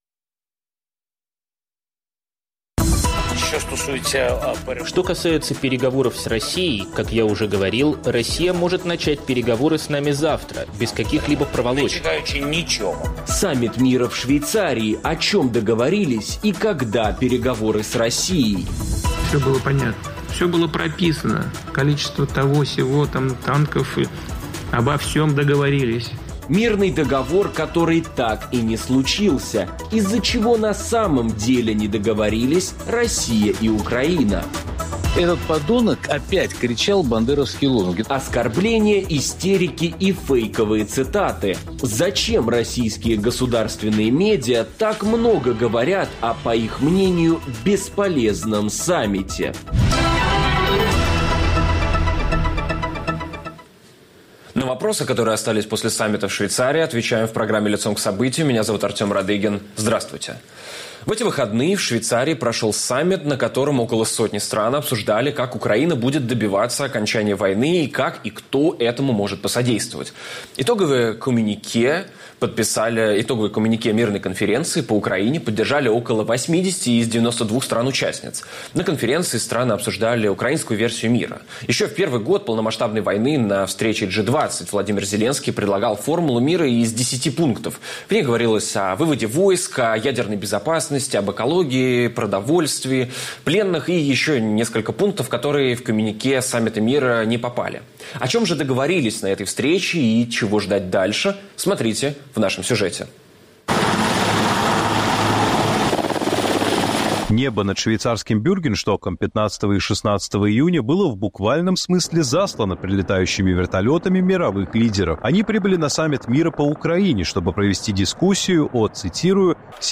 Саммит мира в Швейцарии: о чём договорились и когда возможны переговоры с Россией? И зачем российские государственные медиа так много говорят о, по их мнению, бесполезном саммите? Попробуем разобраться с политическим аналитиком